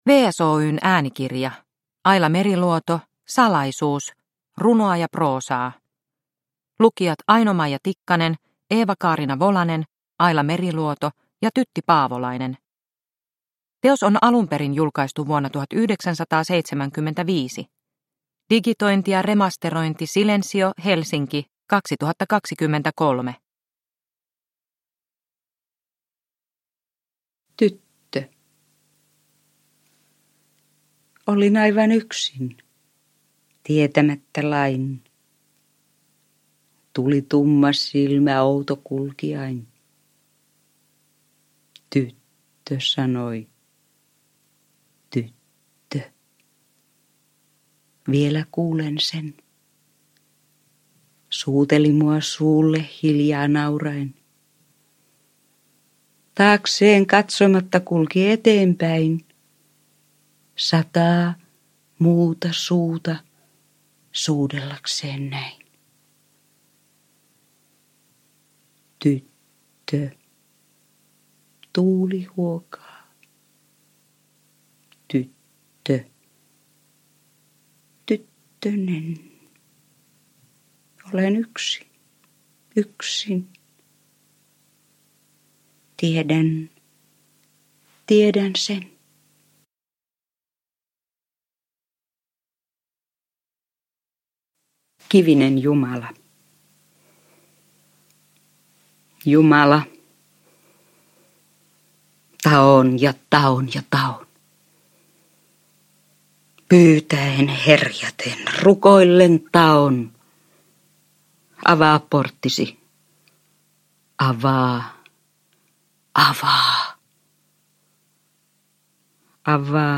Aila Meriluodon vaikuttavimpia tekstejä näyttelijöiden ja kirjailijan itsensä tulkitsemina.